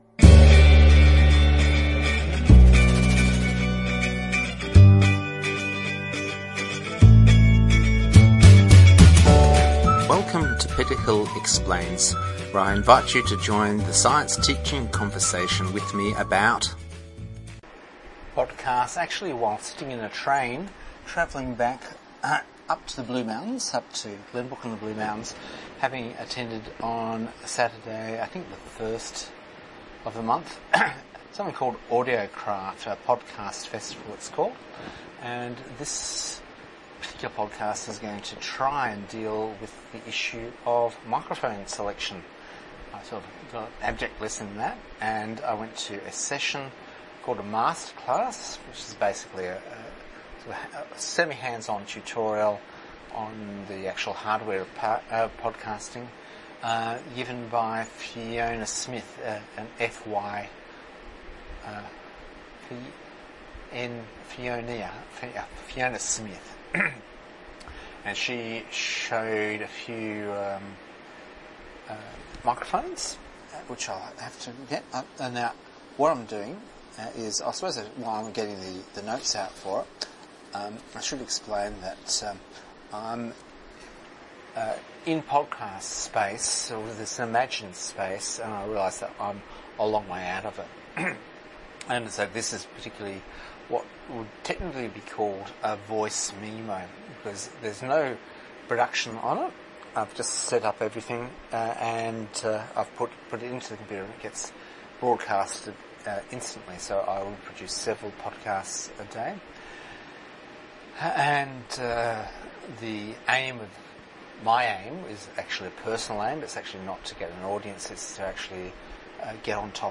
I went to a Master Class in how podcasting should be done..... and found out that I am off on my own doing crazy things for a good cause... me and my rehab. But even though I am breaking all the rules and voice memoing I still struggle finding the right microphone.